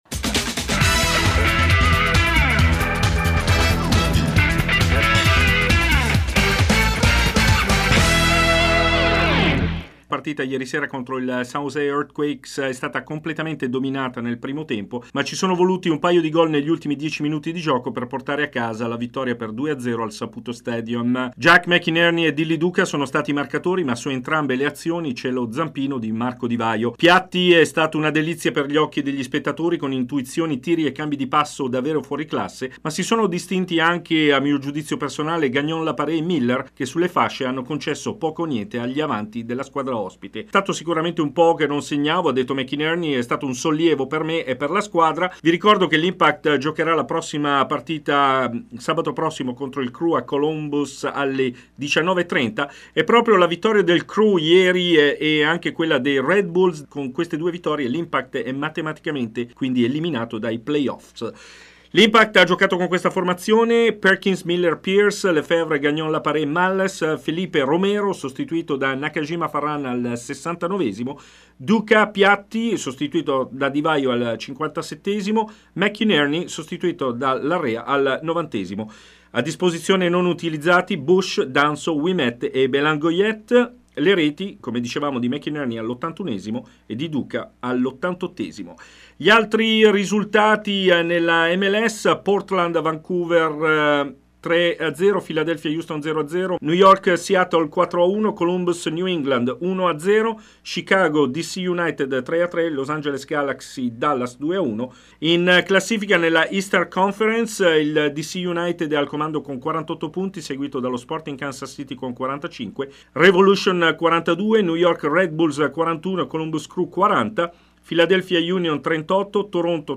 Servizio completo con le interviste